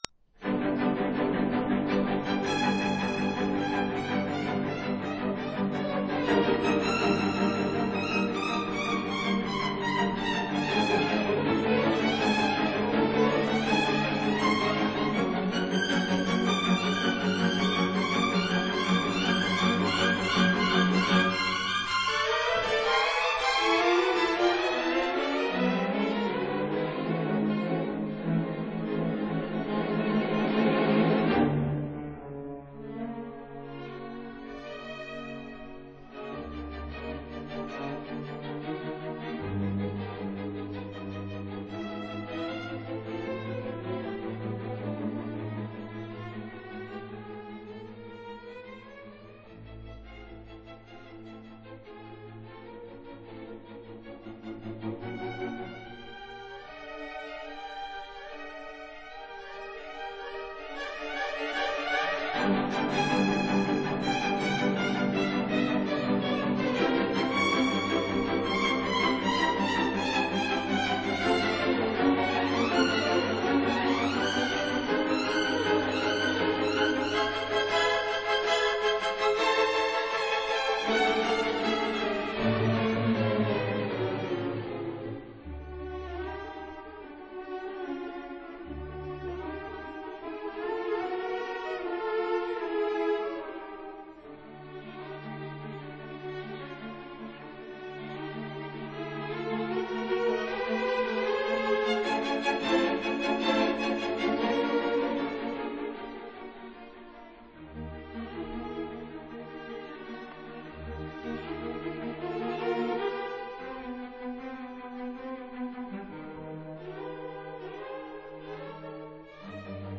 (08-11) Concerto for strings